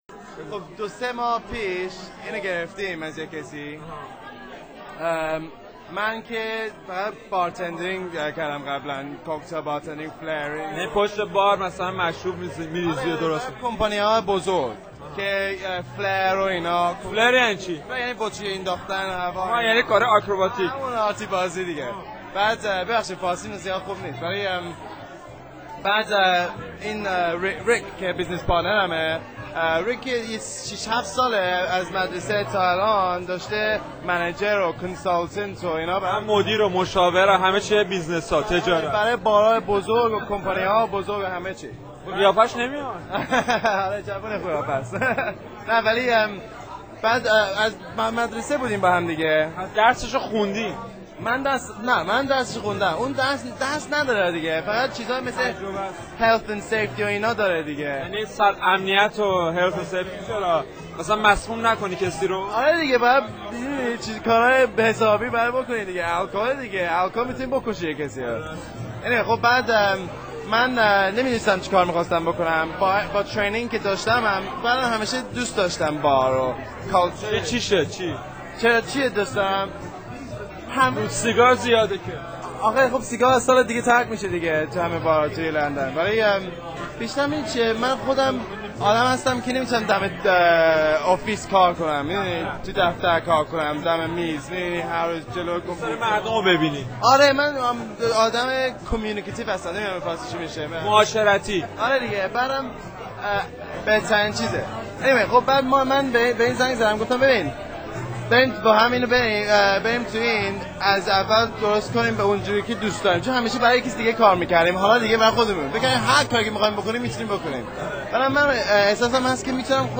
حرفهائی که با هم زدیم رو بشنوین ( راز موفقیت می خونه)